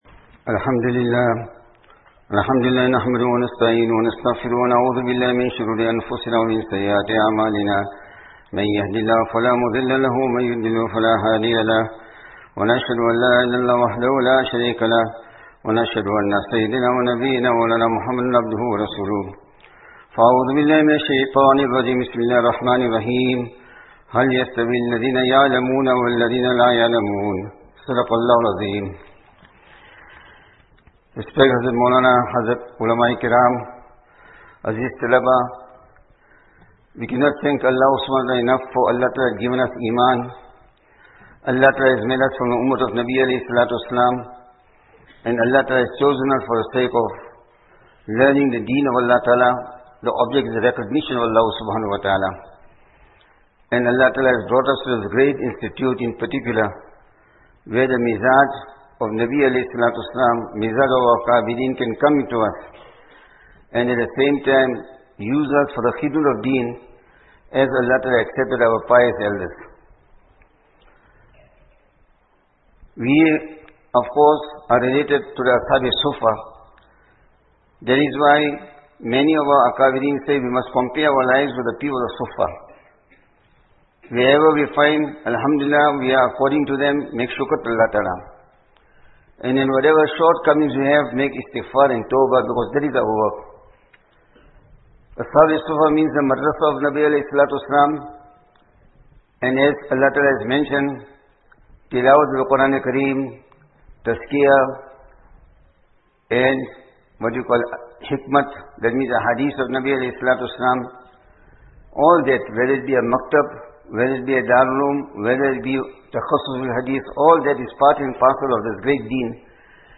19 Sha'ban 1445 / 01 March 2024 Annual Jalsa Jummah Bayaan – Our Akabireen, Immaterial of the Conditions that Prevailed They Remained Firm on Sunnah & The Commands of Allah | DUA | Darul Uloom Azaadville | Madrasah Arabia Islamia